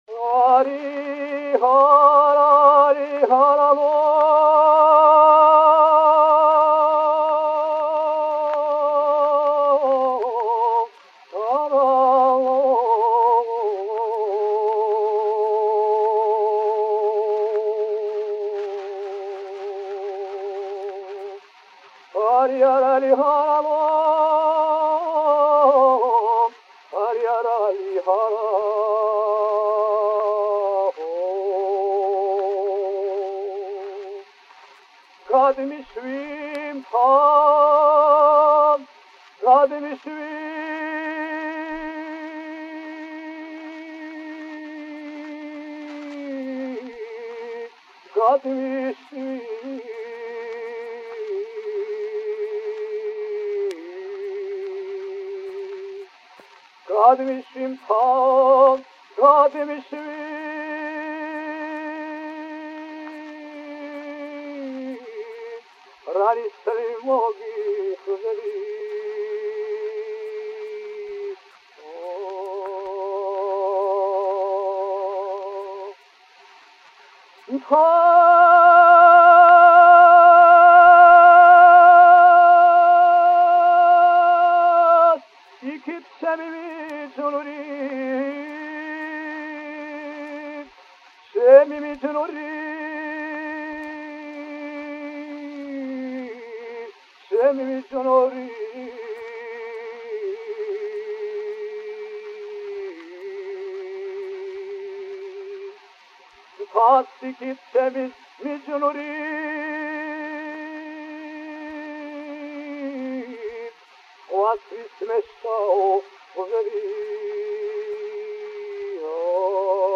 The following four songs were recorded from the LP:
(recorded 1909)